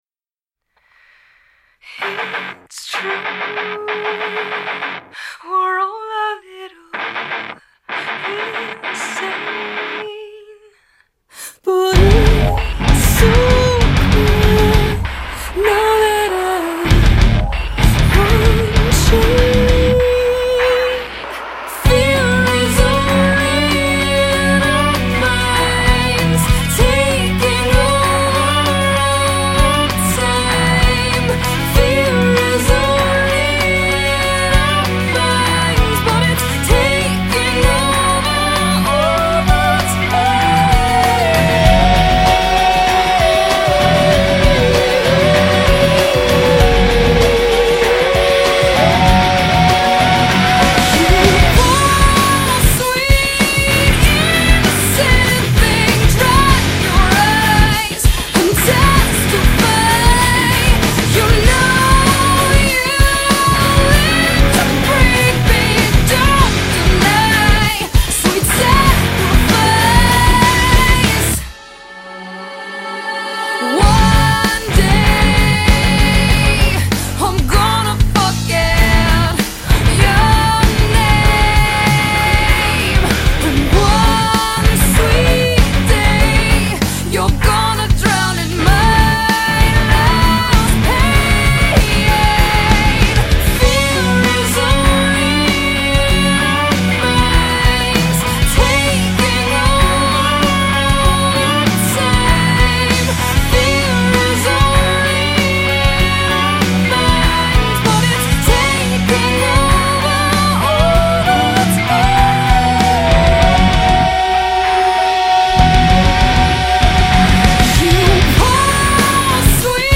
سبک موسیقی آلترناتیو راک